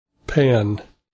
click this icon to hear the preceding term pronounced.